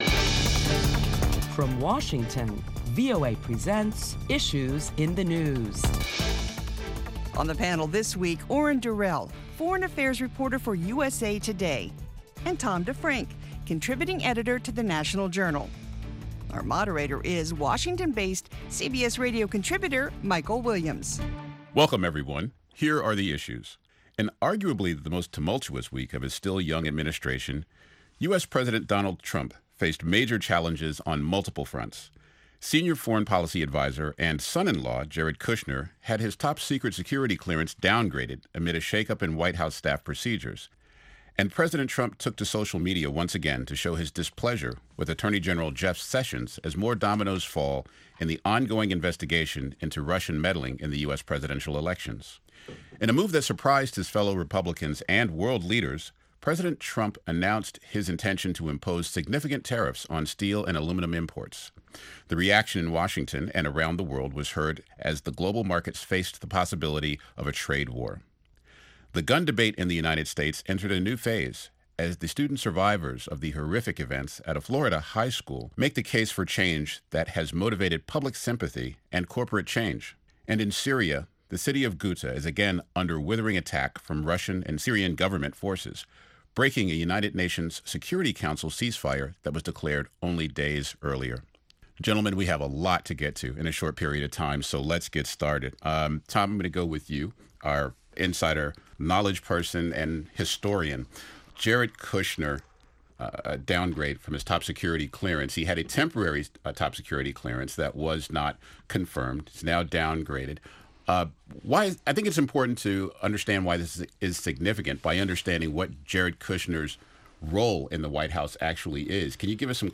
This week on Issues in the News, top Washington journalists talk about the week's headlines including the latest on the Russia probe and the possibility of a trade war with new tariffs announced on steel.